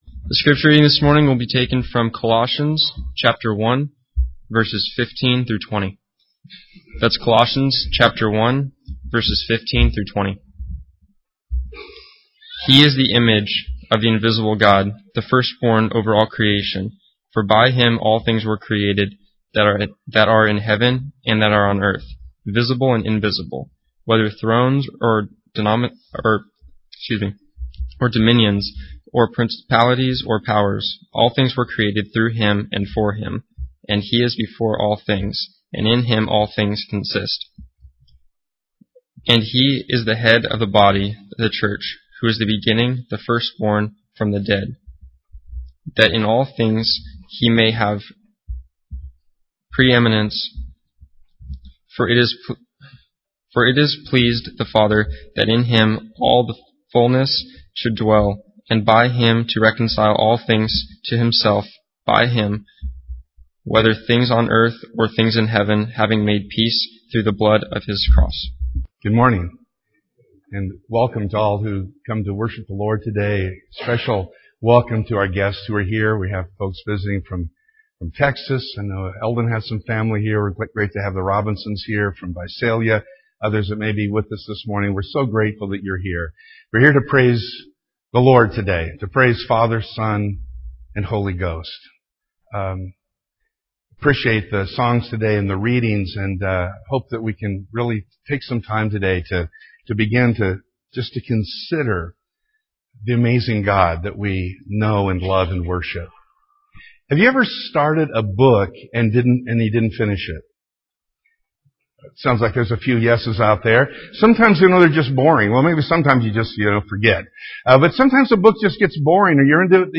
The reading is from Colossians 1: 15-20.